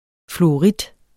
Udtale [ fluoˈʁidˀ ]